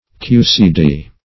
QCD \QCD\ (k[=u]"s[=e]*d[=e]"), n. (Physics)